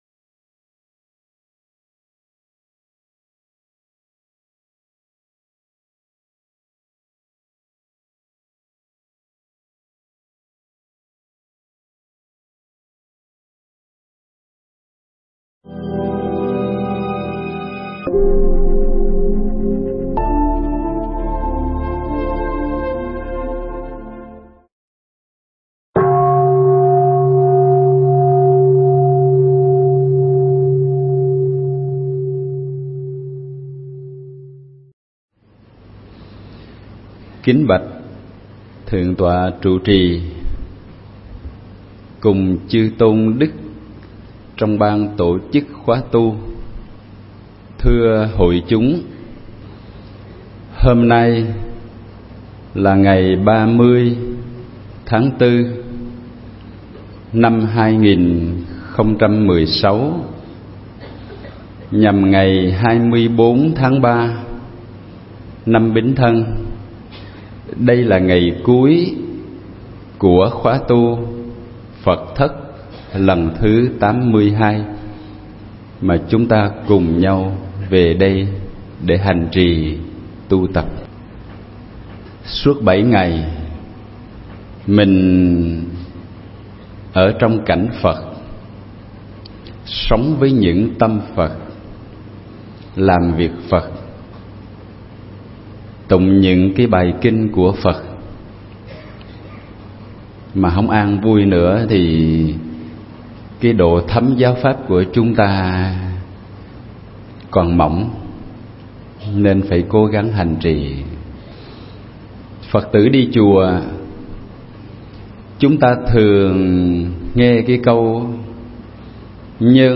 Nghe Mp3 thuyết pháp Cho Trọn Chữ Người